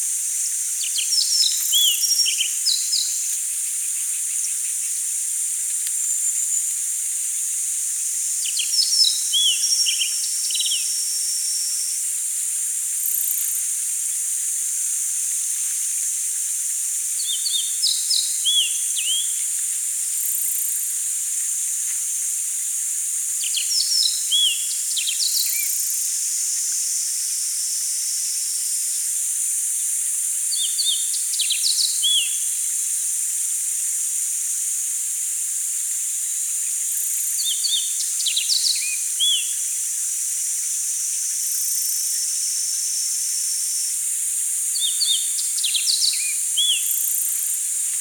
10.ノジコ [Emberiza sulphurata]
soundノジコ (mp3 0分48秒)14時53分過頃収録 花輪火附森